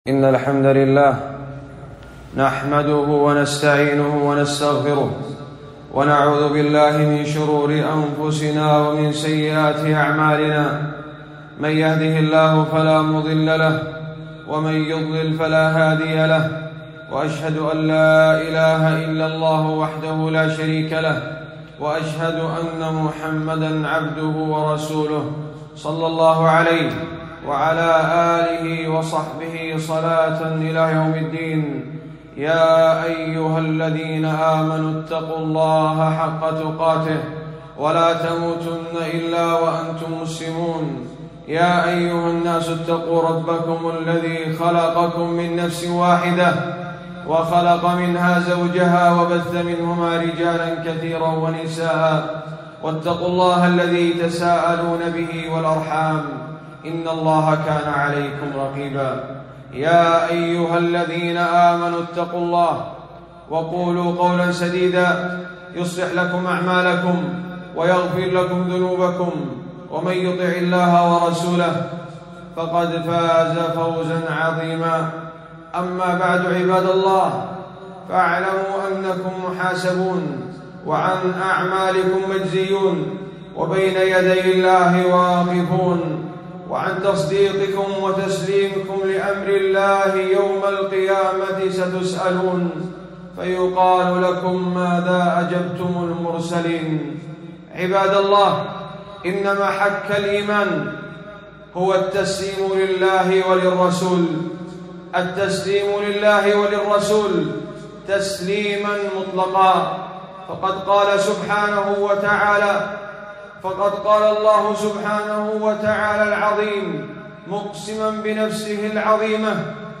خطبة - (ويسلموا تسليما)